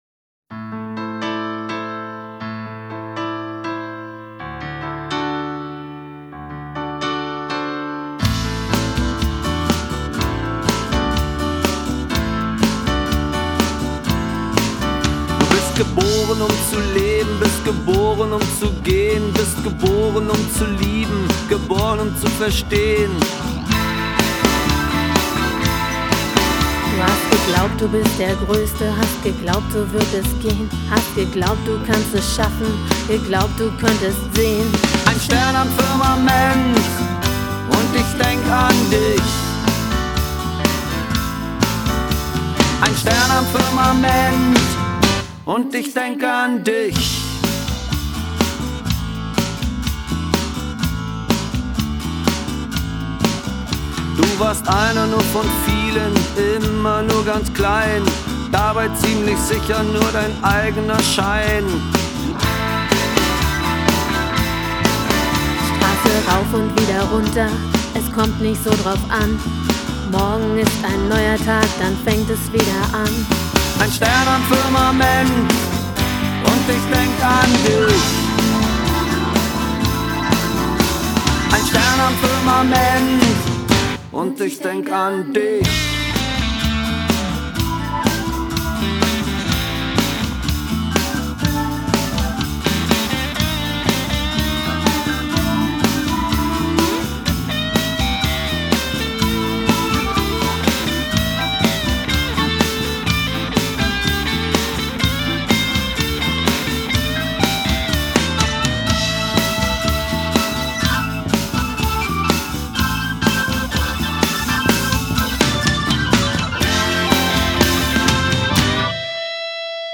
• Rockband
• Allround Partyband